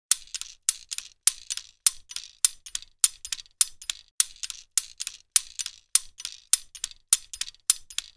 Rotating Sprinkler Loop, Garden, Outdoor 3 Sound Effect Download | Gfx Sounds
Rotating-sprinkler-loop-garden-outdoor-3.mp3